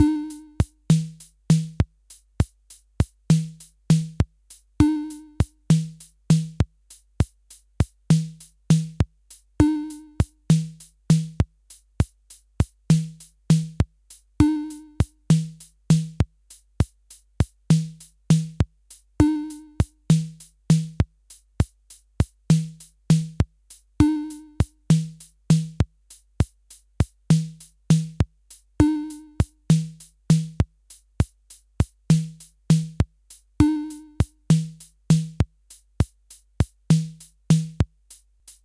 Bucle de percusión electrónica
Música electrónica
repetitivo
sintetizador